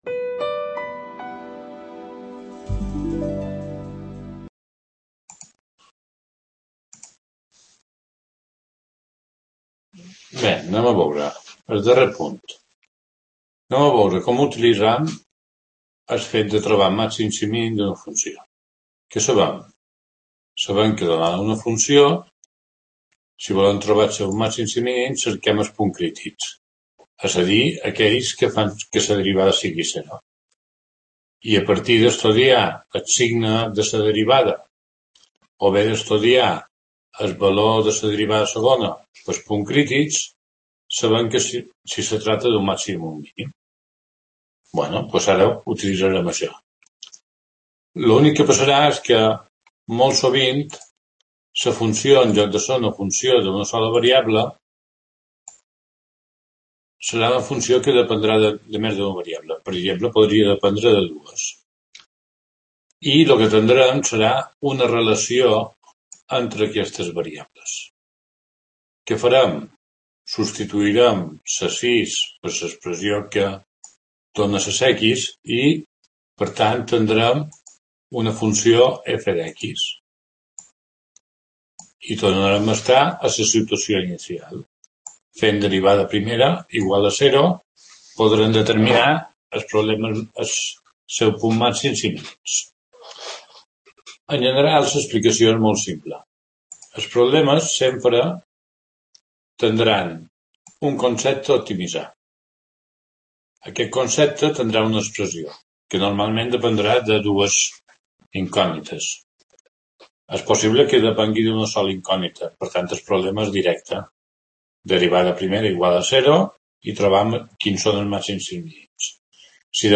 Video clase publico Creative Commons: Reconocimiento - No comercial